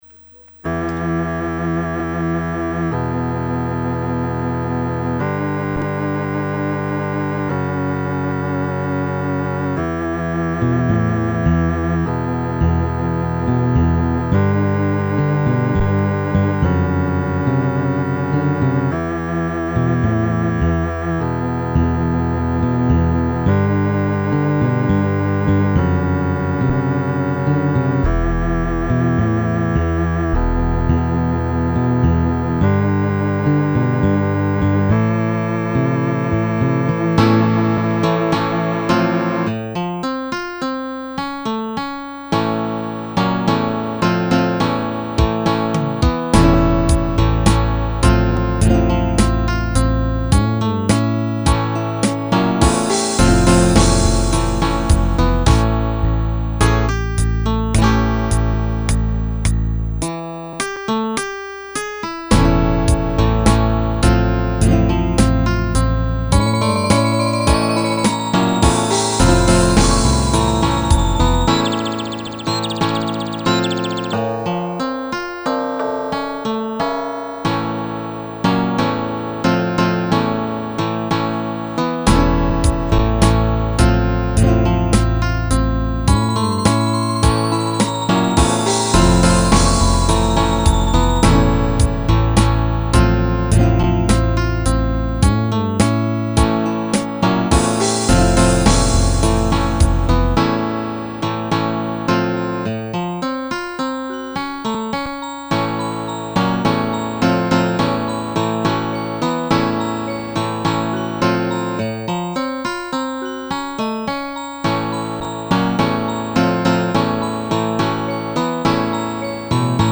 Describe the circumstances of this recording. Y estan en MIDI, algun dia estaran grabadas.